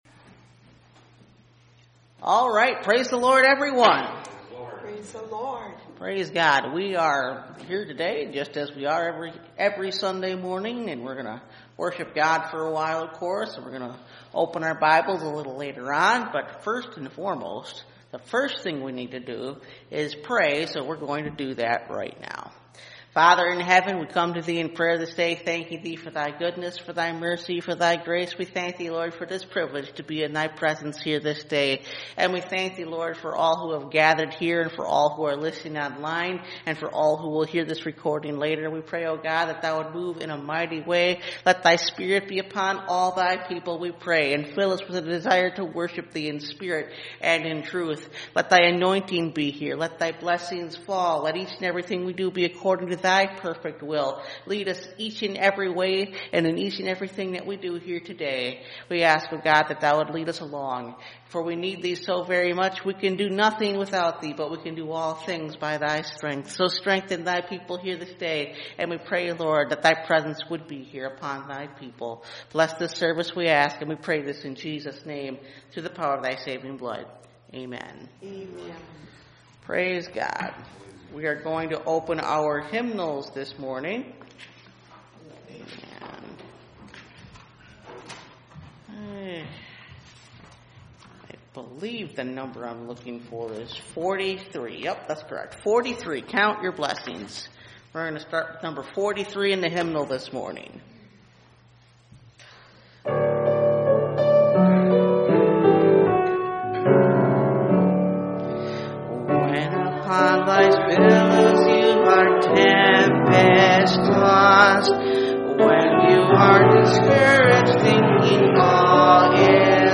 Strength From Weakness – Part 1 – Last Trumpet Ministries – Truth Tabernacle – Sermon Library
Service Type: Sunday Morning